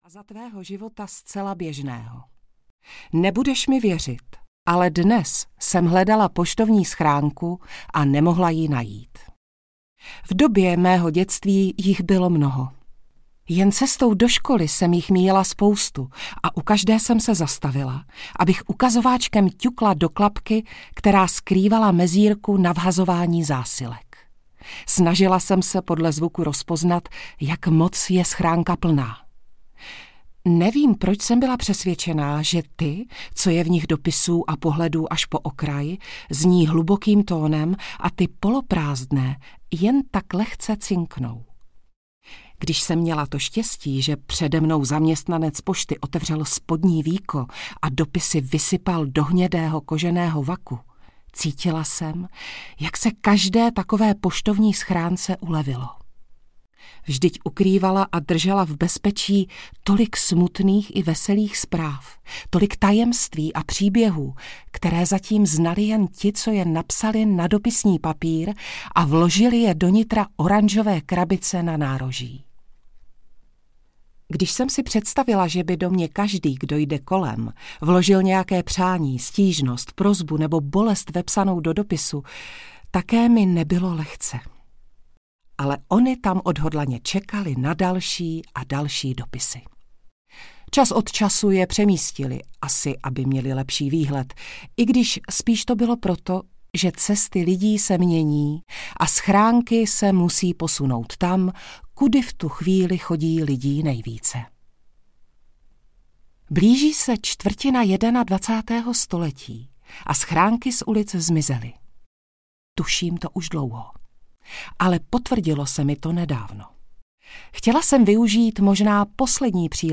Tvá M. audiokniha
Ukázka z knihy
• InterpretMartina Preissová